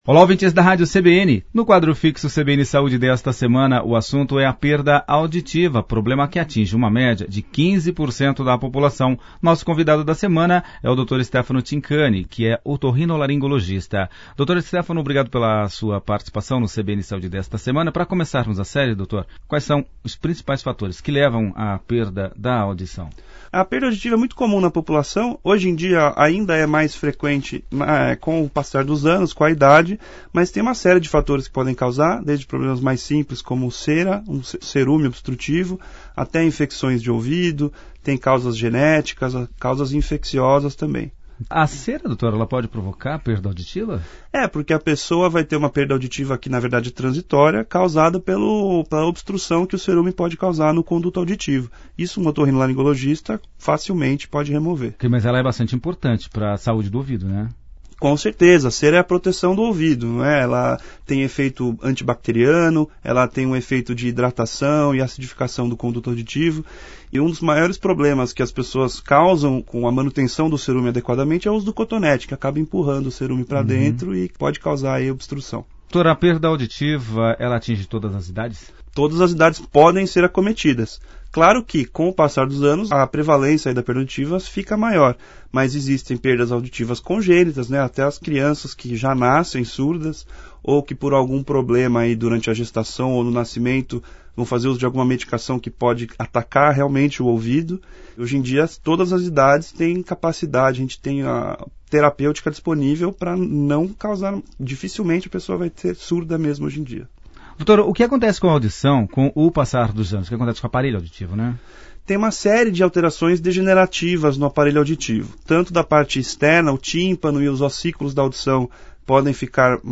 A Entrevista foi ao ar no dia 09 de Setembro de 2019